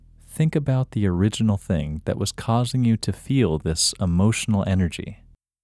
IN – Second Way – English Male 20